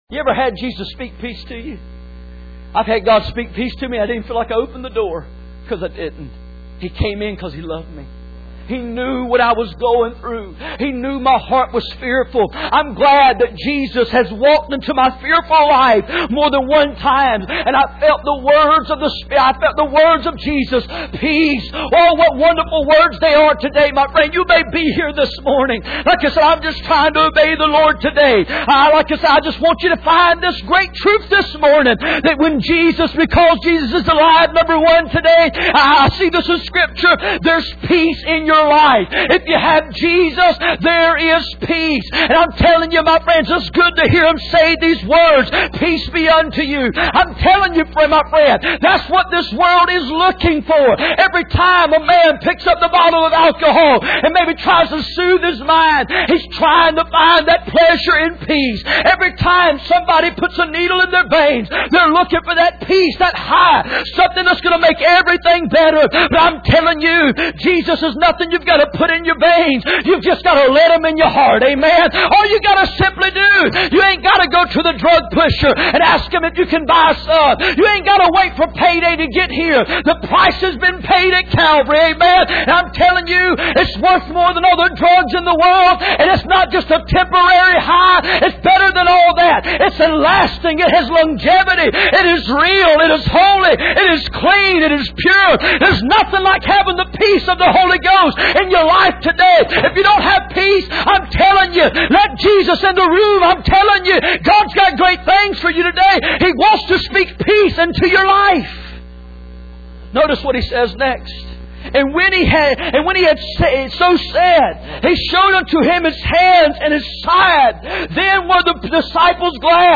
None Passage: John 20:19-28 Service Type: Sunday Morning %todo_render% « Christian Doctrine Lord